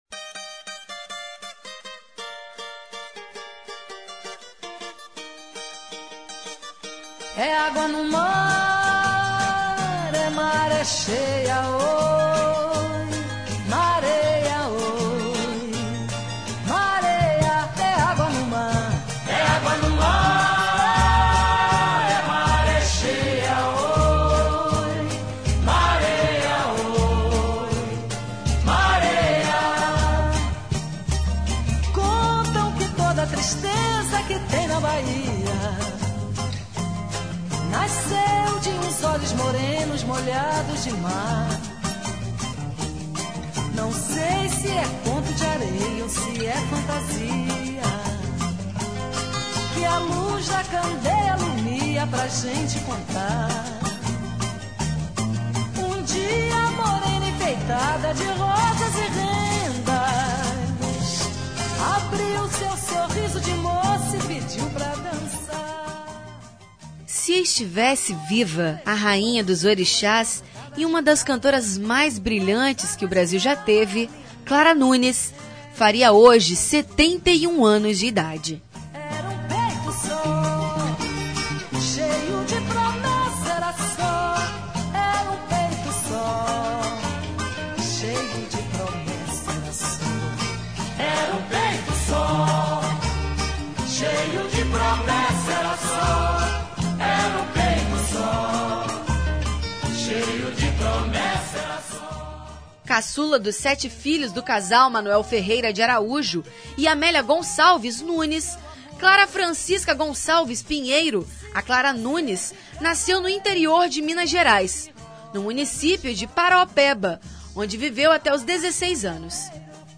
Para homenagear a mineira, o Revista Universitária preparou uma matéria especial.